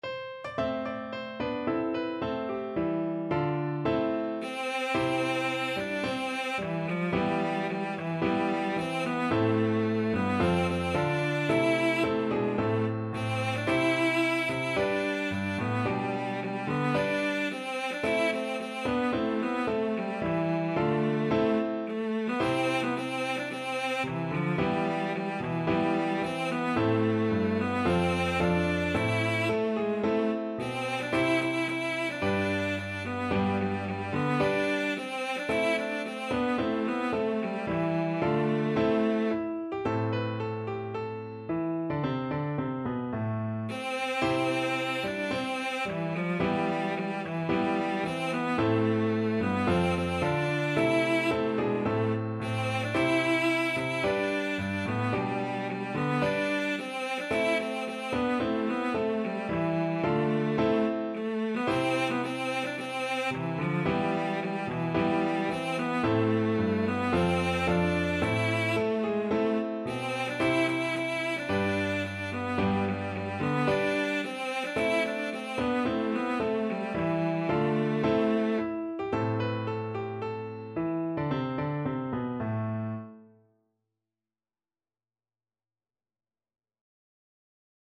Free Sheet music for Cello
Cello
4/4 (View more 4/4 Music)
A minor (Sounding Pitch) (View more A minor Music for Cello )
~ = 110 Allegro (View more music marked Allegro)
Traditional (View more Traditional Cello Music)